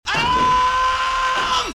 alaaaaaaaarm.mp3